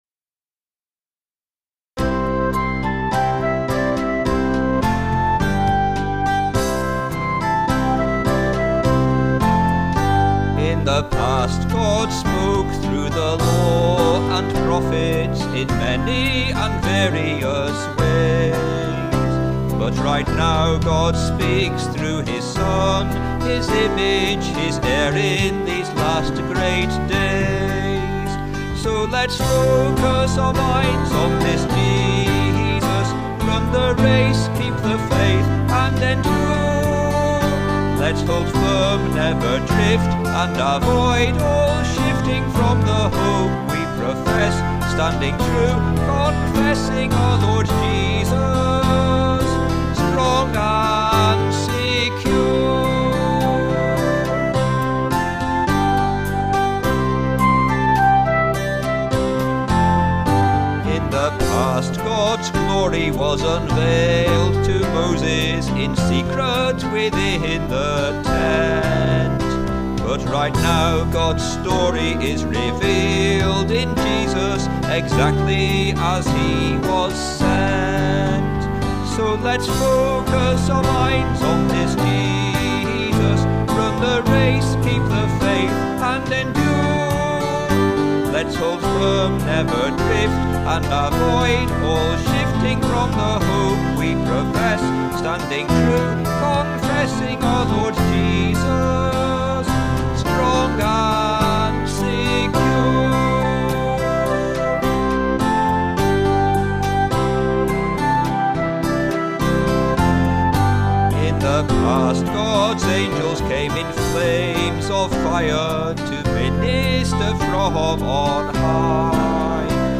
contemporary hymn-song
but I wrote this somewhat similar but more guitar-based music